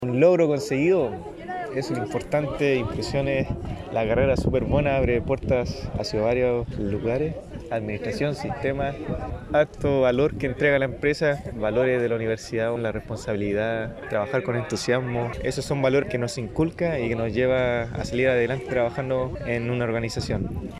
La actividad se llevó a cabo en el Auditorio del Campus Guayacán nuestra casa de estudios, hasta donde llegaron directivos, profesores, personal de la apoyo a la academia, y familiares para acompañar en tan importante momento a los graduandos.